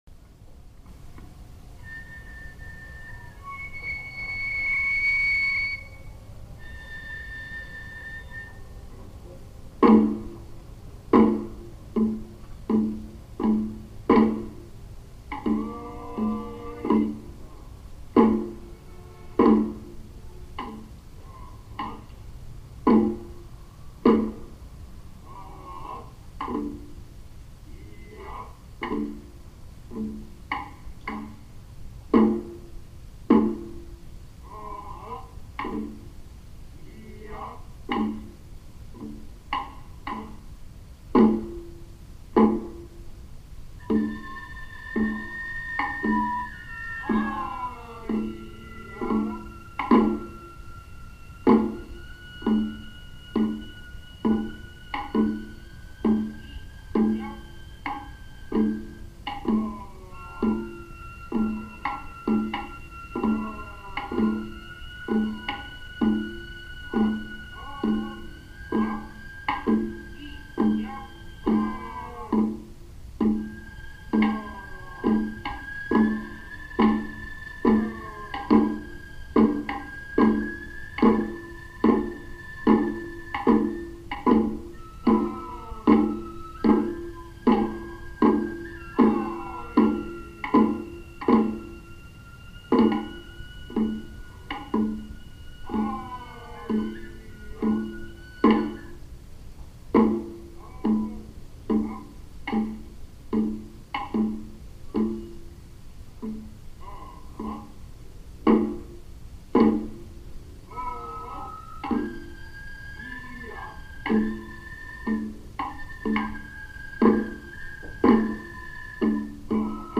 小太鼓，鼓，能管で演奏されます。
小太鼓，鼓，能管のそれぞれの演奏をよく聞いて演奏しないとタイミングがずれることがあります。演奏の難しい囃子です。